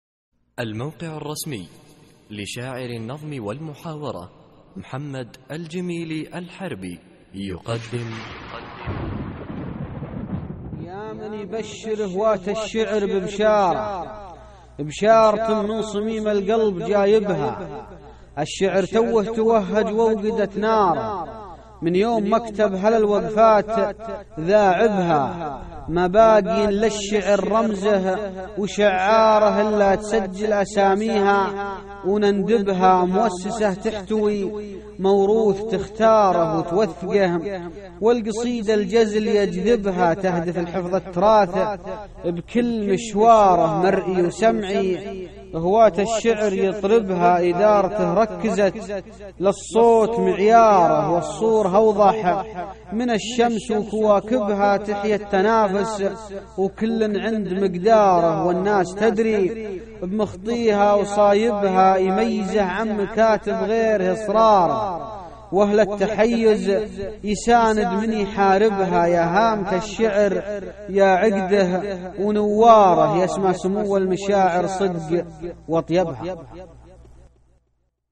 القصـائــد الصوتية
اسم القصيدة : بشارة ~ إلقاء